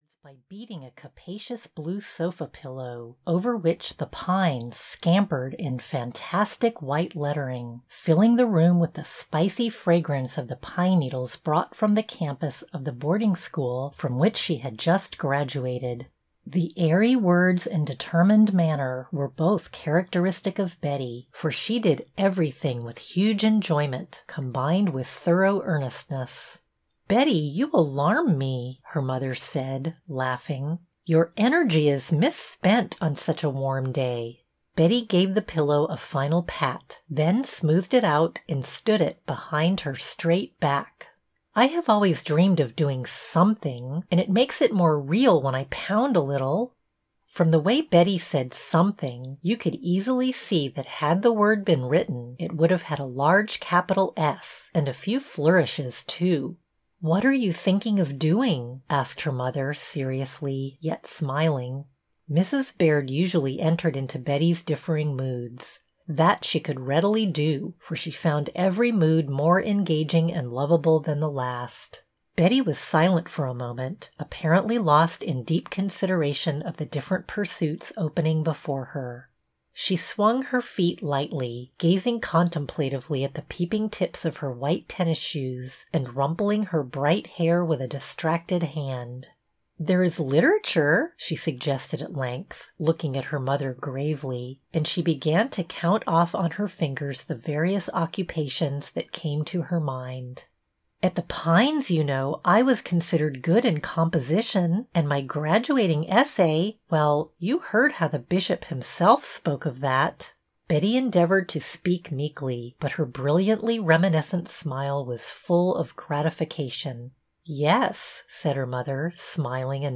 Betty Baird's Ventures (EN) audiokniha
Ukázka z knihy